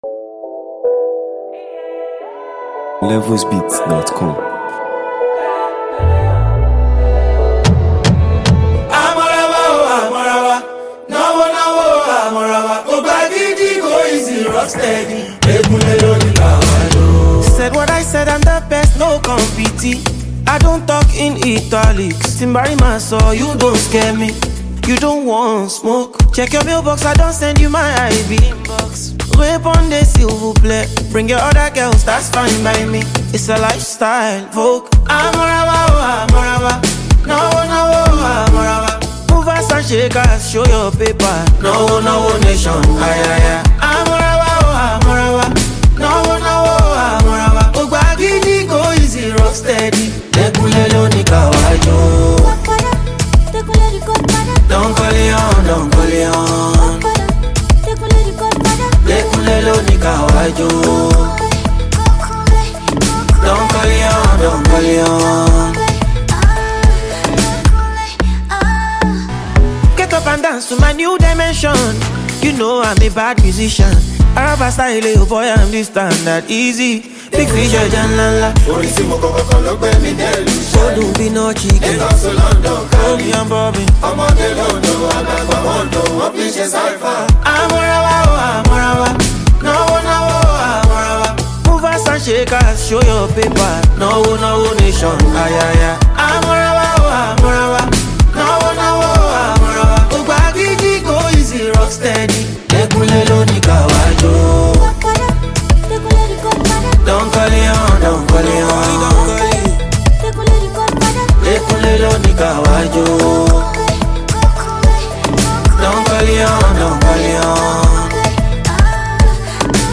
Nigeria Music 2025 2:26